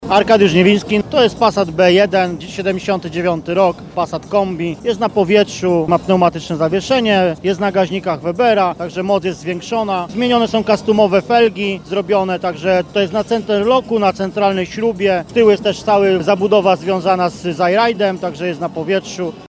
Ryk silników, pisk opon i maszyny takie, że dech zapiera. W hali pod Dębowcem w Bielsku-Białej trwa Moto Show 2023 – największa impreza motoryzacyjna w tej części Europy.